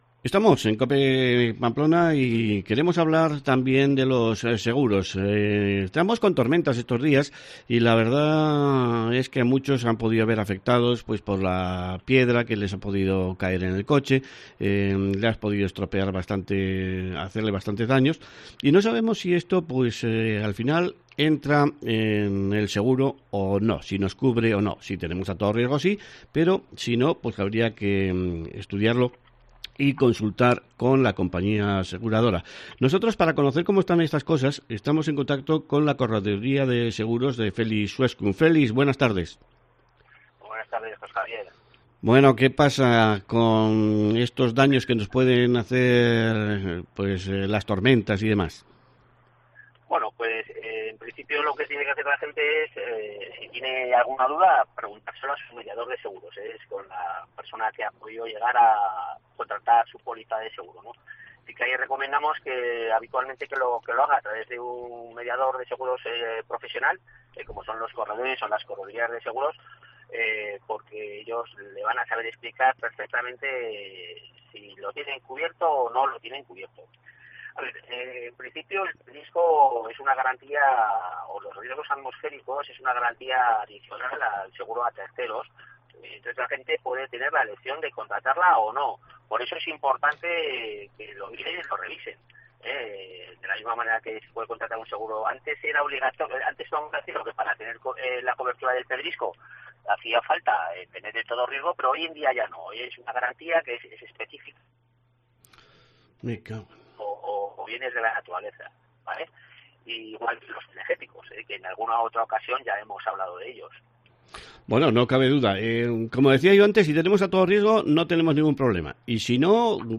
corredor de seguros, sobre la actualidad de los seguros edl automóvil: las inclemencias climatológicas y los seguros de los nuevos coches eléctricos.